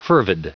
Prononciation du mot fervid en anglais (fichier audio)
Prononciation du mot : fervid